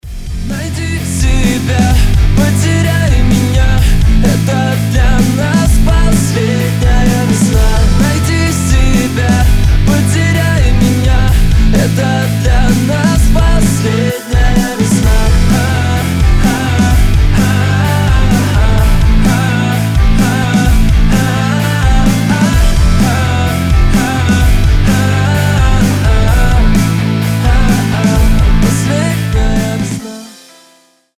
• Качество: 320, Stereo
мужской голос
Alternative Rock
emo rock